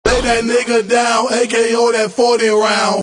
Tm8_Chant41.wav